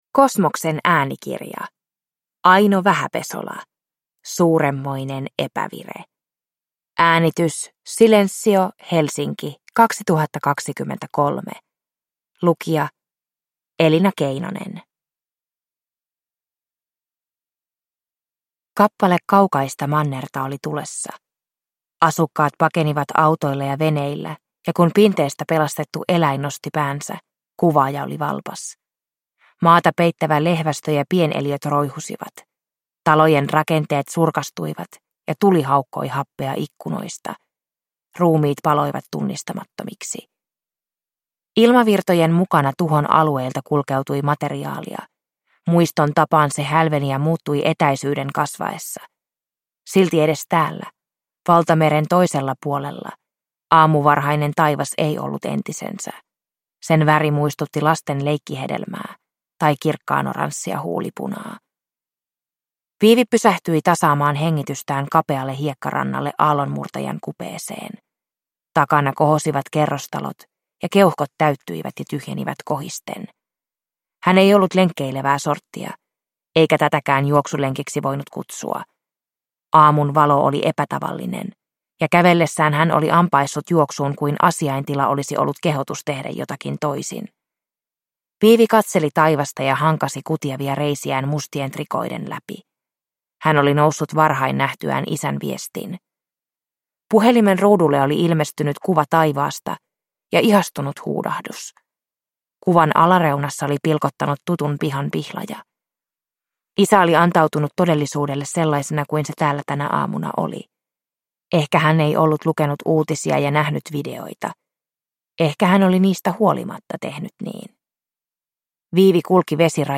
Suurenmoinen epävire – Ljudbok – Laddas ner